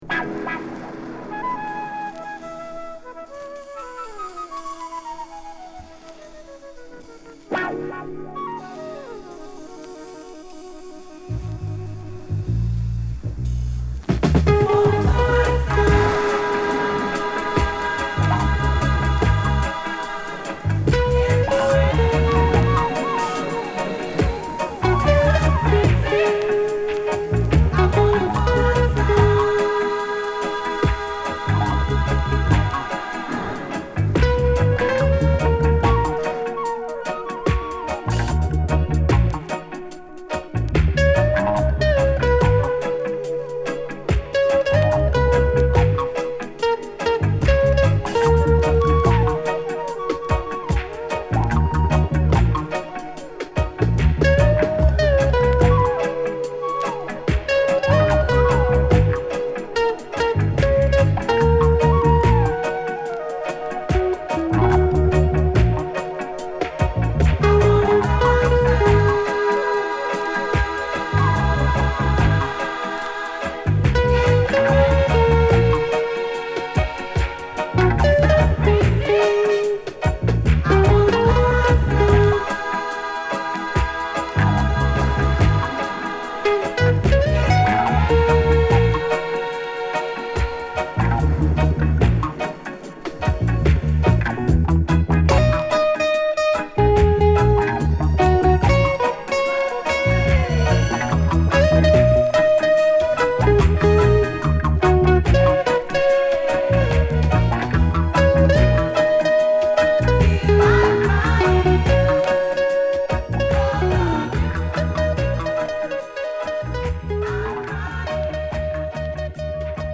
米国マイアミ録音、メロウ・レゲエ～ディスコレゲエ～ラヴァースロックを横断する名作
雨音で始まり、ラストも雨音で締める